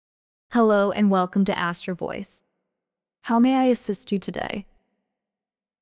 default_feminine.mp3